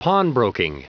Prononciation du mot pawnbroking en anglais (fichier audio)
Prononciation du mot : pawnbroking